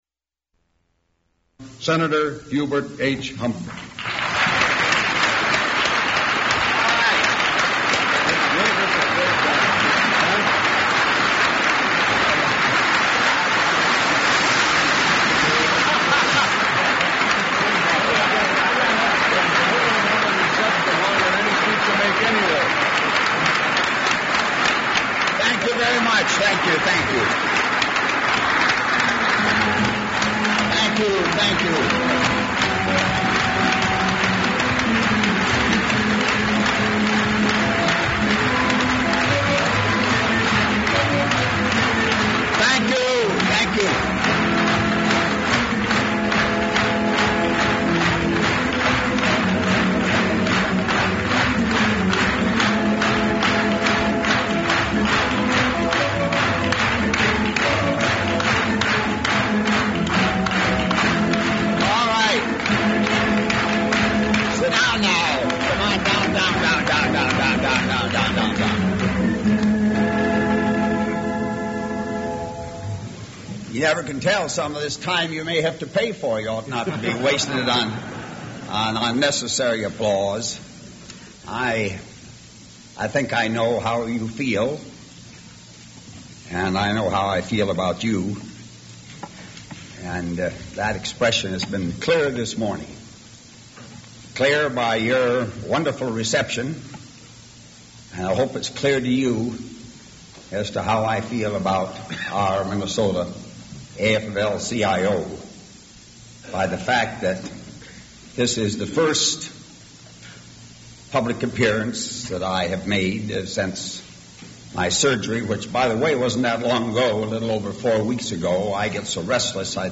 Broadcast in January, 1978.